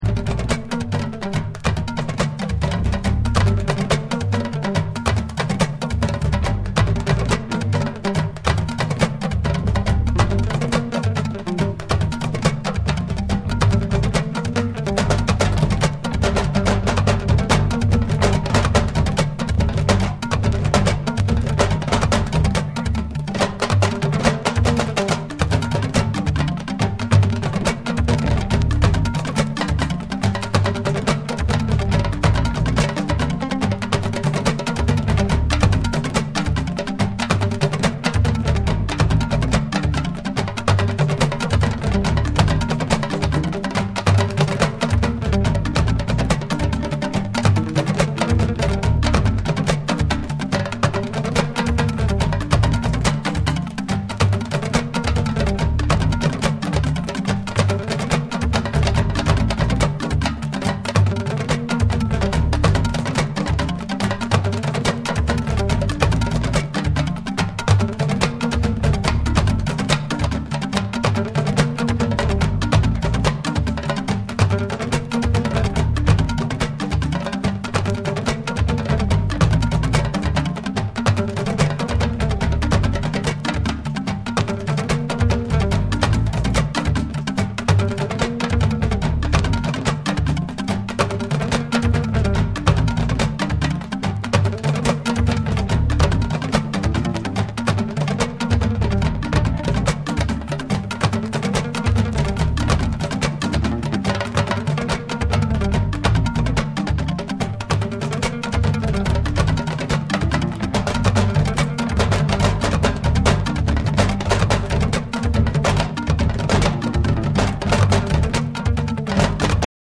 エネルギッシュなパーカッションと深みのあるベースが生み出すグルーヴが圧倒！
WORLD